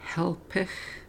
hálp'ex eating something (short of a social meal) Open this word in the Galloway dictionary Listen to the elders